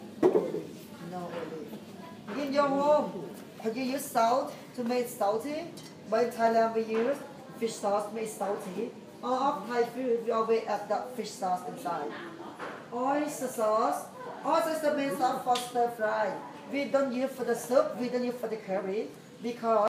여튼 그랬다. 다섯시부터 시작한다던 요리교실에 나는 부랴부랴 다섯시가 조금 넘은 시간 헐떡헐떡 거리며 헬멧을 벗을 틈도 없이 들어갔고, 아시아인은 나밖에 없었고, 모두 남편과 아내 손을 잡고 온 이들이고, 혼자인 건 나와 내 옆옆자리에 앉은 스위스 여자아이뿐이고, 너무 급하게 달려와 게다가 땡볕에 너무 오래 있어 더위를 목젖까지 퍼먹은 바람에 머리가 아팠고, 콧물도 나오고.